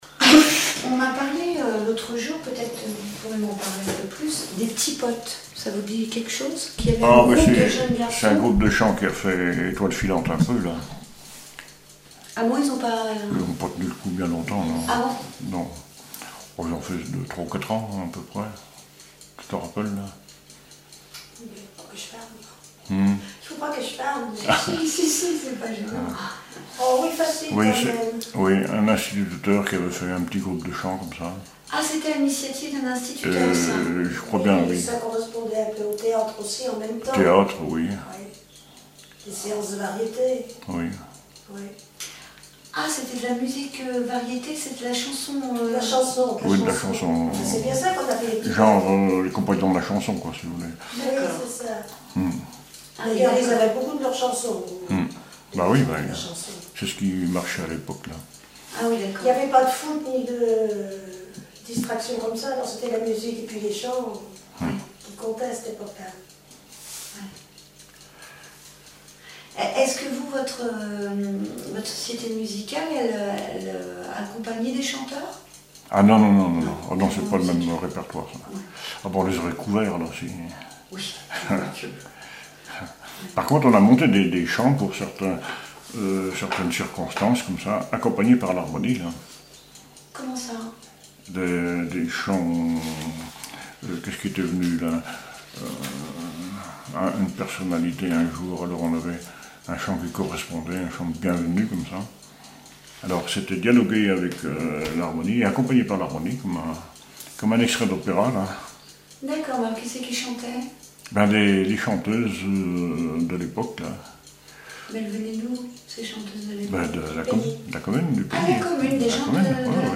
témoignages sur la musique et une chanson
Catégorie Témoignage